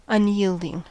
Ääntäminen
US : IPA : [ʌn.ˈjil.dɪŋ]